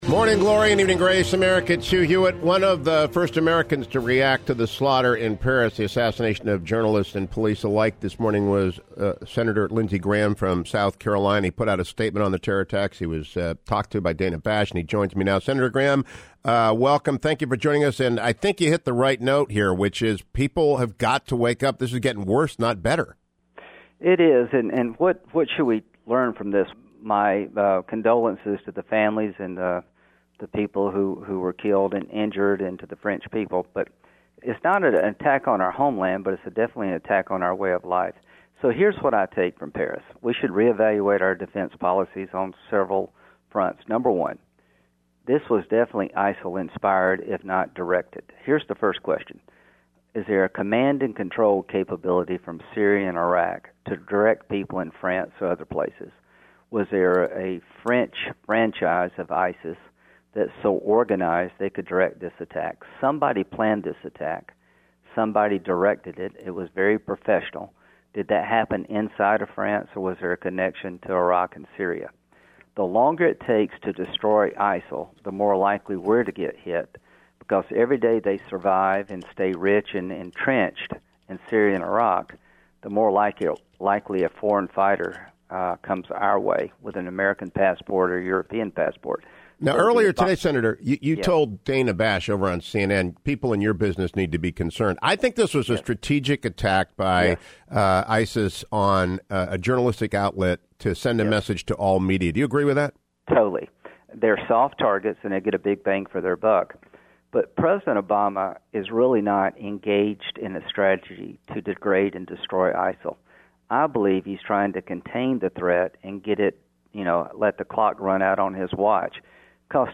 South Carolina’s Lindsey Graham joined me today for some very blunt talk about the assassinations